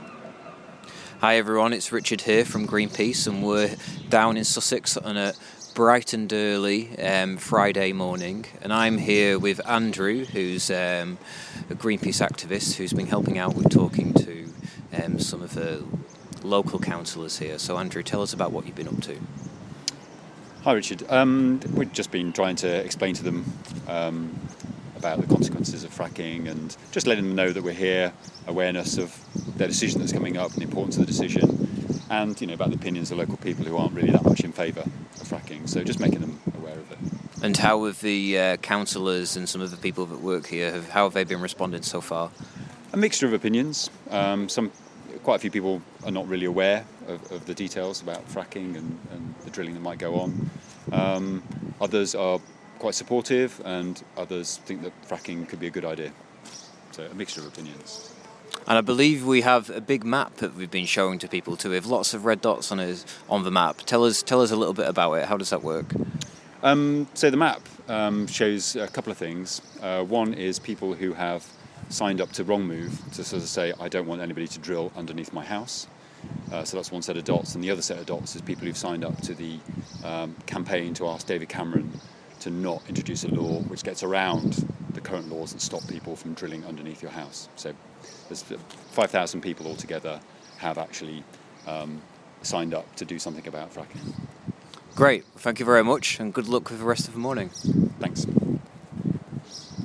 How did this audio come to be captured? This morning, Greenpeace volunteers are outside west Sussex county council, talking to councillors here about plans to frack the area.